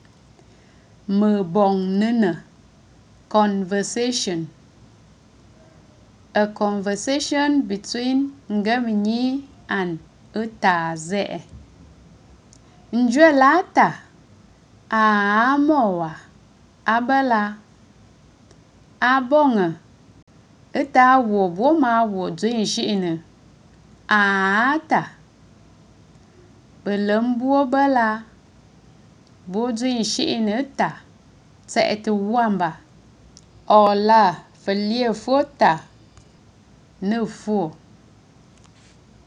Mǝ̀bòŋnǝ̀nǝ̀   – Conversation
42c23-conversation_on_greetings_in_nkwen_language.mp3